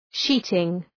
Προφορά
{‘ʃi:tıŋ}